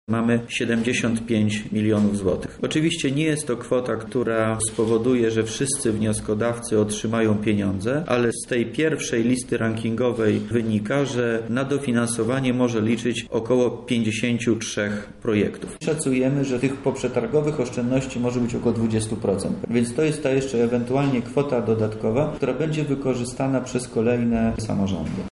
W późniejszym terminie, gdy pojawią się oszczędności po przetargach, można spodziewać się, że pieniędzy wystarczy jeszcze na około 20 projektów – ocenił Wojciech Wilk,wojewoda lubelski